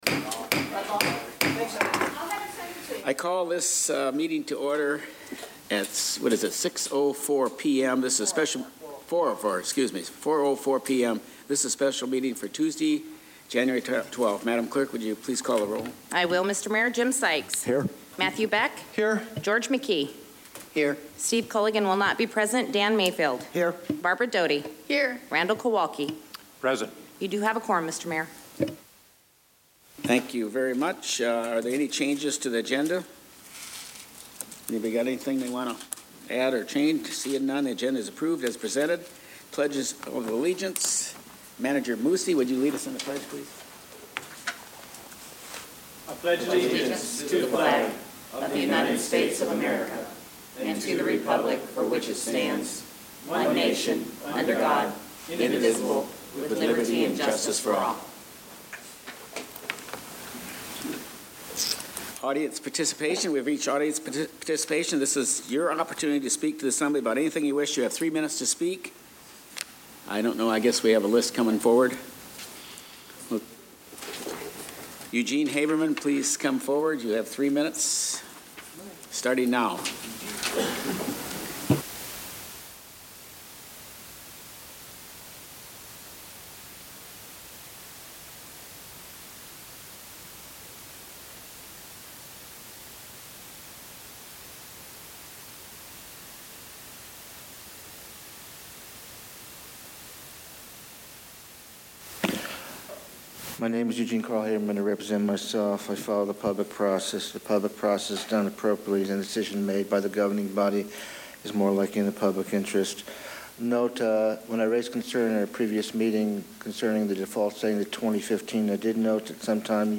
MatSu Borough Assembly Special Meeting: Planning and Zoning 1.12.2016
Jan 15, 2016 | Borough Assembly Meetings